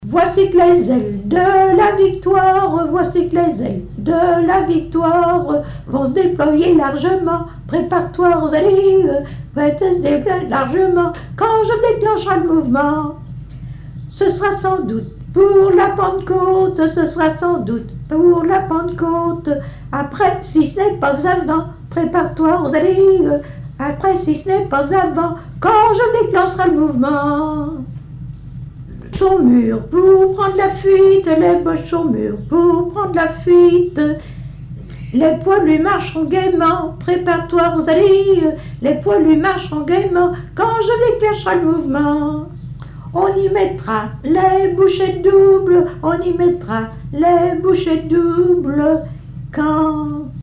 Genre strophique
Témoignages et chansons
Catégorie Pièce musicale inédite